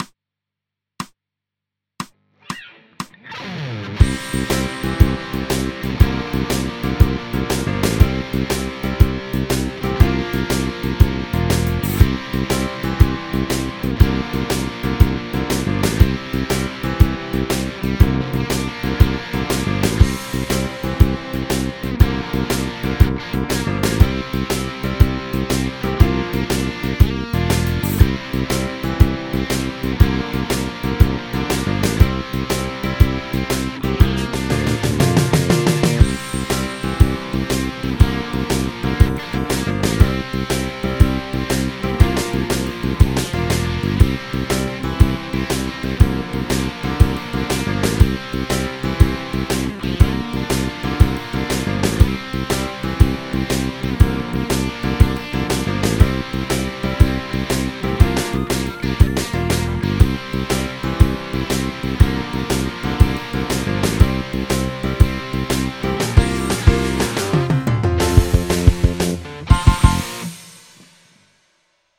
ミクソリディアン・スケール ギタースケールハンドブック -島村楽器